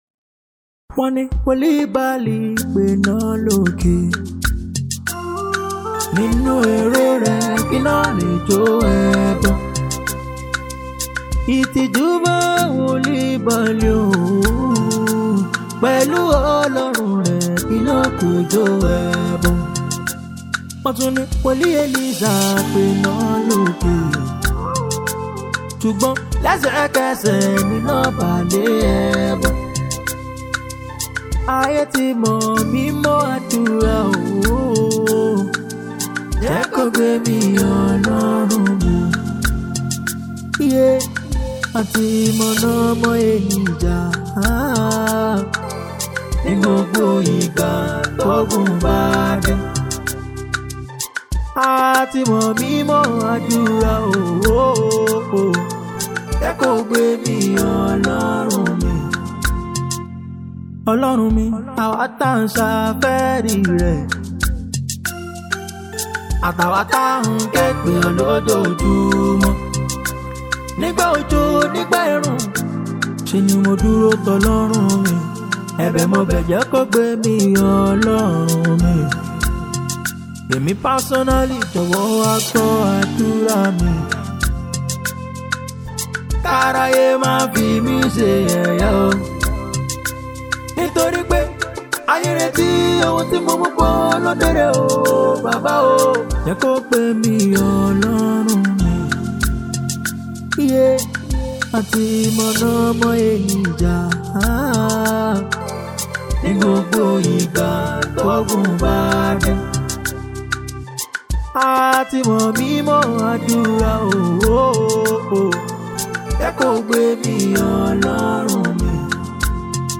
Yoruba Gospel Music
a gifted gospel singer and songwriter.